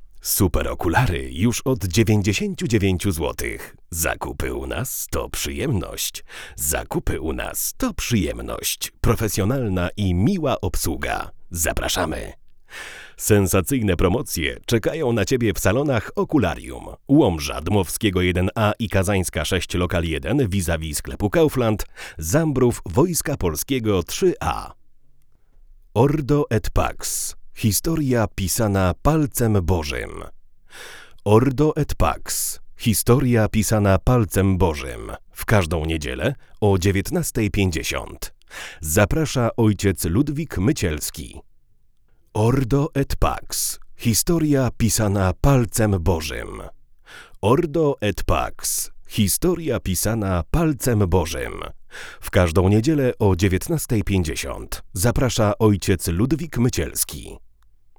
Do testów używamy popularnego Neumanna TLM 103, i dla równowagi U89 tego samego producenta.
Brzmienie tego przedwzmacniacza naprawdę nie pozostawia wiele do życzenia.